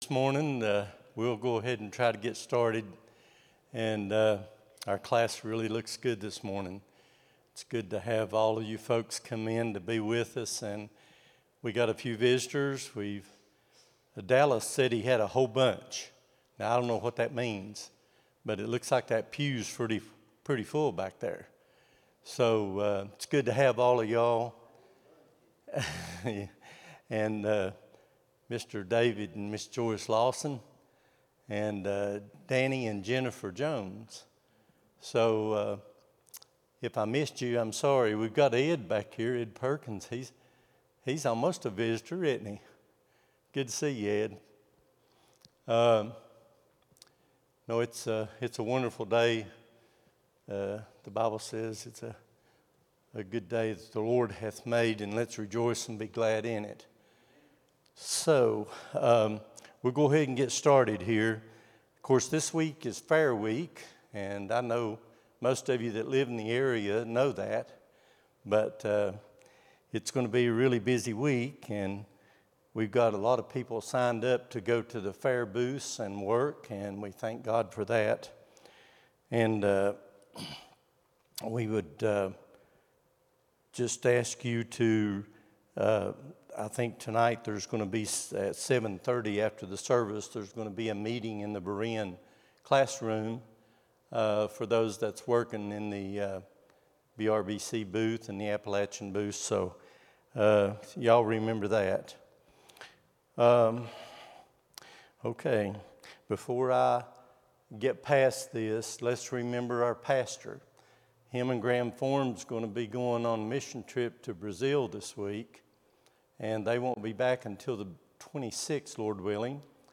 08-17-25 Sunday School | Buffalo Ridge Baptist Church
Sunday School lesson